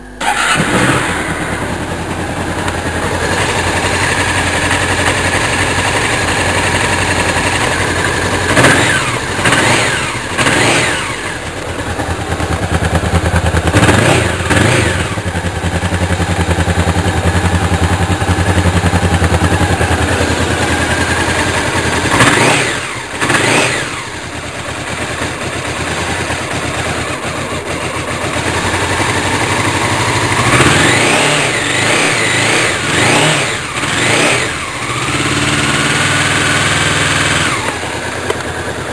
bicylindre parallèle 4 T, refroidi par eau, 2 ACT, 4 soupapes par cylindre ; 499 cm3 (73 x 59,6 mm) ; rapport volumétrique 10,5 à 1 ; 57,1 ch à 9 500 tr/mn ; 4,6 mkg à 8 000 tr/mn ; boîte 6 v. ; transmission secondaire par chaîne.
Vous pouvez aussi écouter le moteur ( fichier wav de 839Ko).